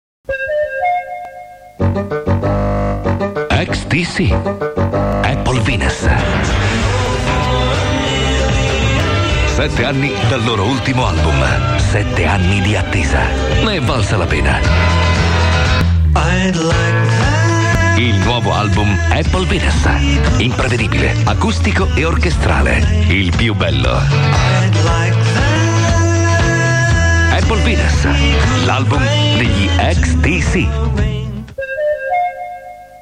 Ascolta lo spot radiofonico - Italian radio promo